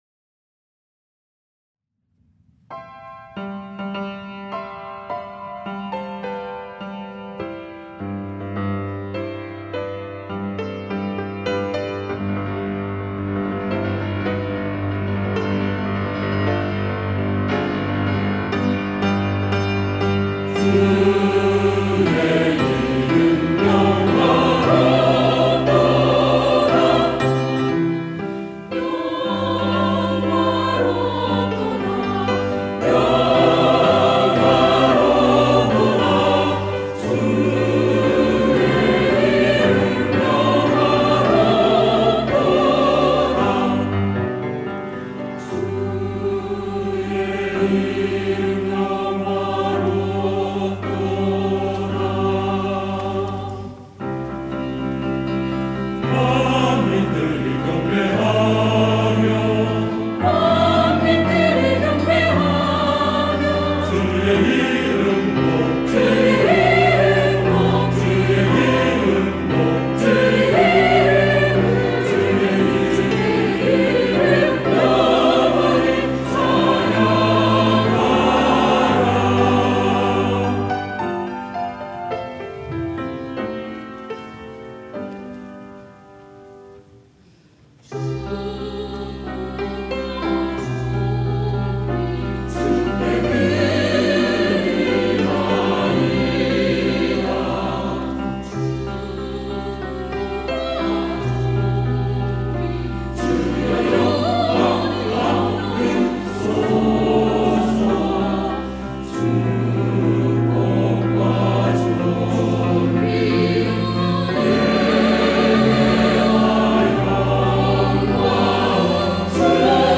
할렐루야(주일2부) - 주의 이름 영화롭도다
찬양대